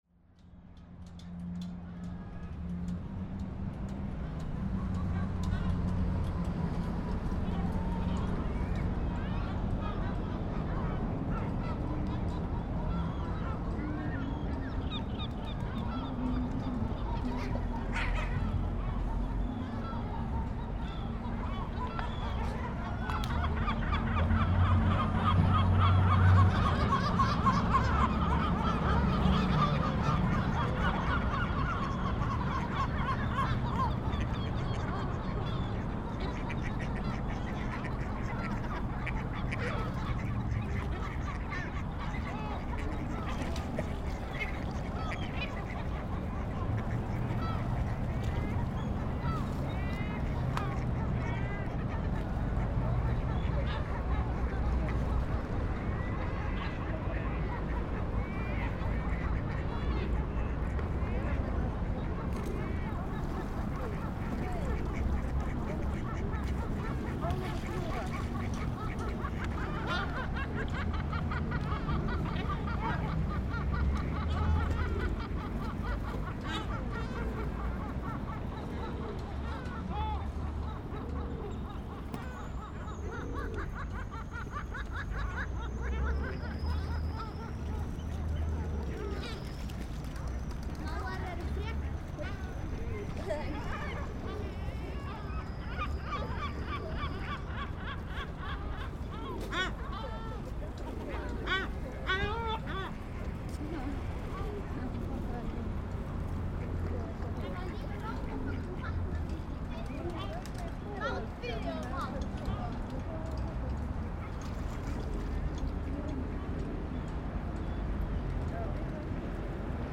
It was a accordion day and members of the Accordion club of Reykjavik was playing in the city hall. When I arrive it was overcrowded and the sound quality was poor from the amplified monitors. So I decided to stay outside and listen instead to the birds on the pound, close to the hall. This recording contain my walk beside the pond, from the southeast side (as seen on the picture) trough the city hall to the northwest side, into very different atmosphere.